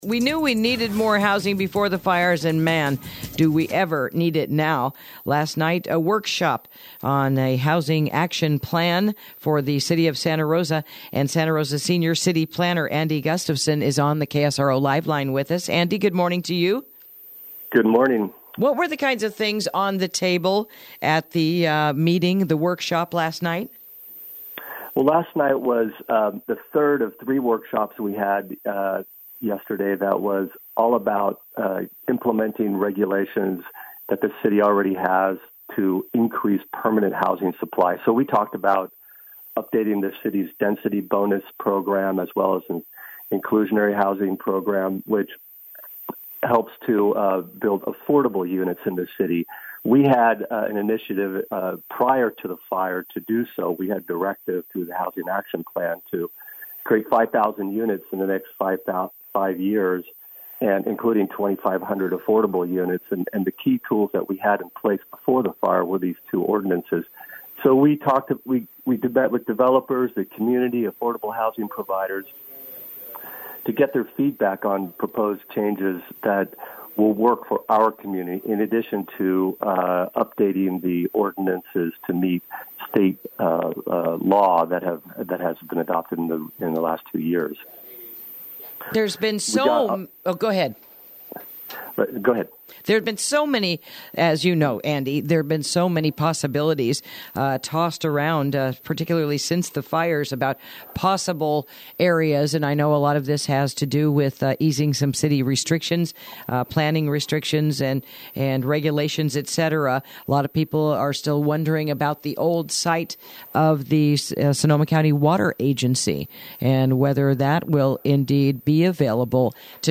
Interview: Housing Action Plan Community Workshop Recap